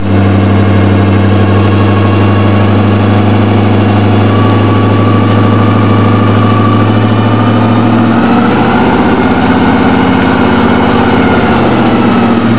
These are with the new 2 1/2" Turbo Tubes.
Inside cab  139 kb.